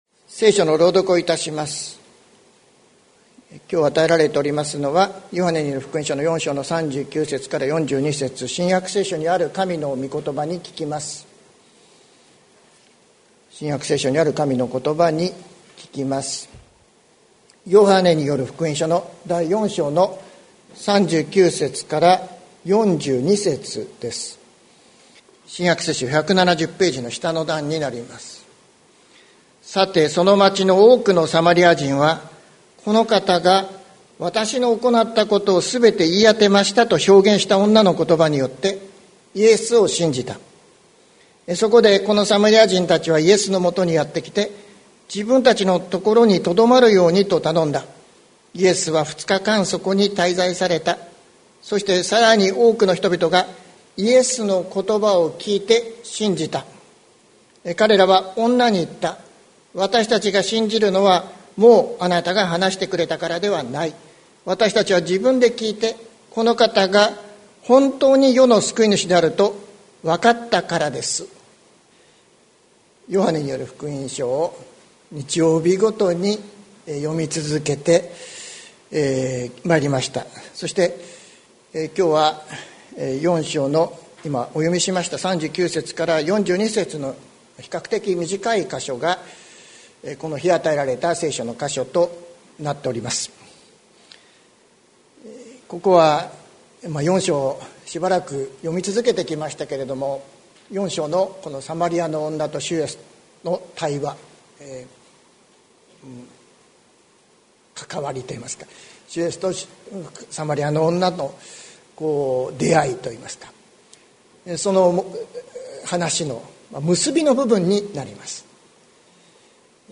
2022年02月20日朝の礼拝「聞くこと、信じること」関キリスト教会
説教アーカイブ。